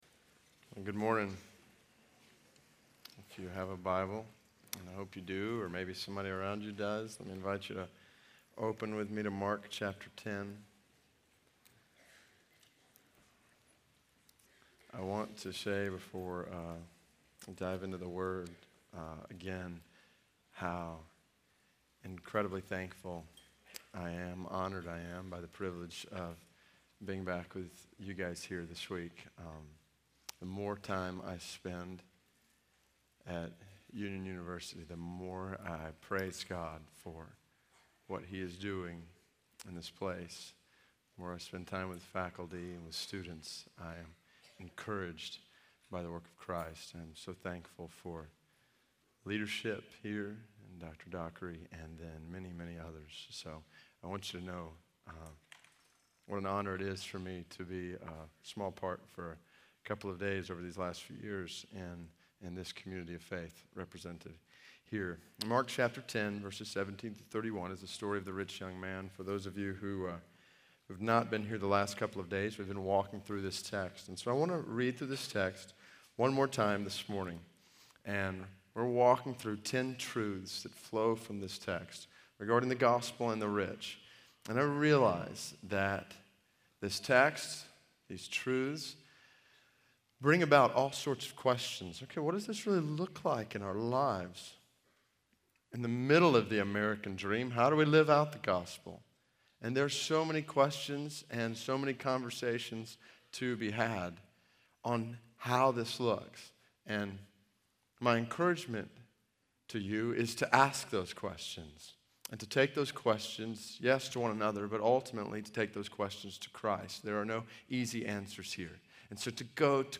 Faith in Practice Chapel: David Platt